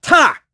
Siegfried-Vox_Attack4.wav